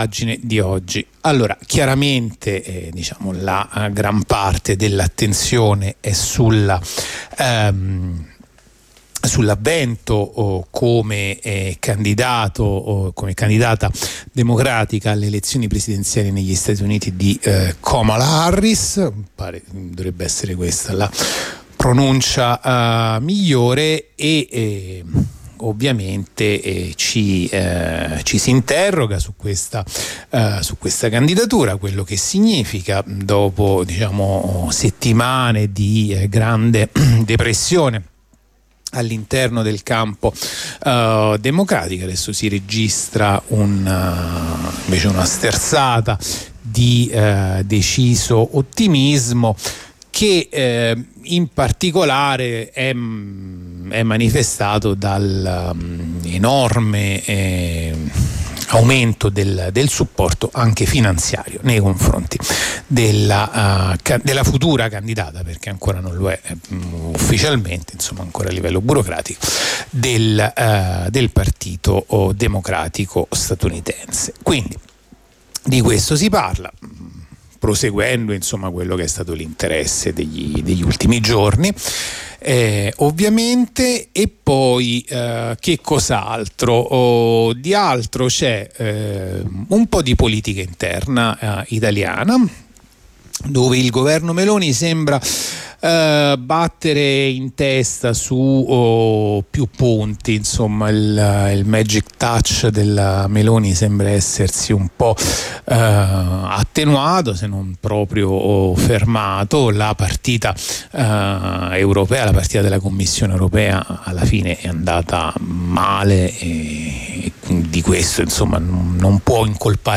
La rassegna stampa di radio onda rossa andata in onda martedì 23 luglio 2024